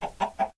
chicken_say1.ogg